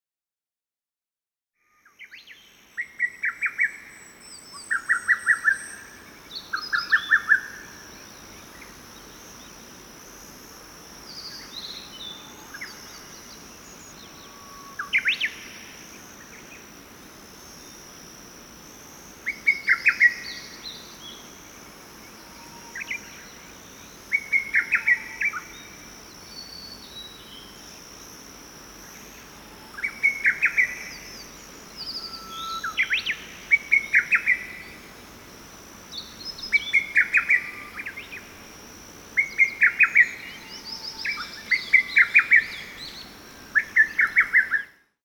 環境音（※７）ノイズを用いて、脳が耳鳴りを「重要な信号」として認識しないように再学習させる、脳の可塑性を利用したアプローチです。